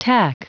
Prononciation du mot tack en anglais (fichier audio)
Prononciation du mot : tack